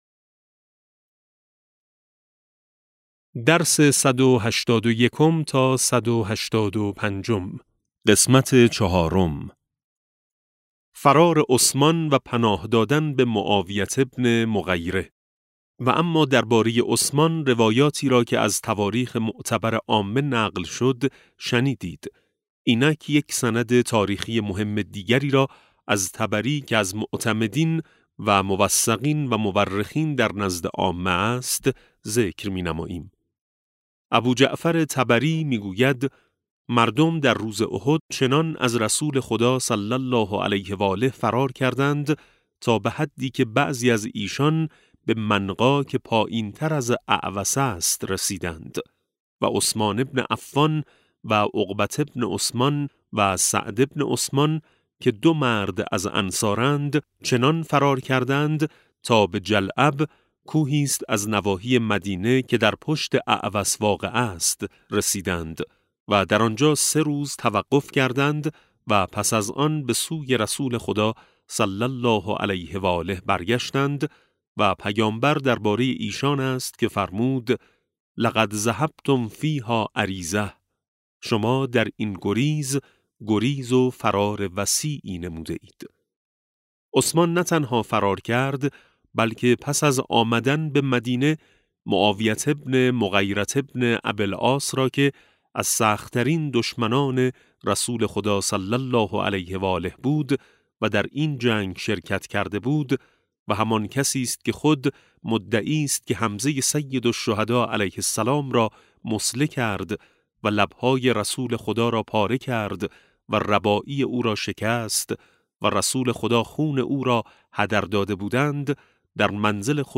کتاب صوتی امام شناسی ج 13 - جلسه4